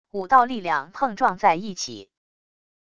五道力量碰撞在一起wav音频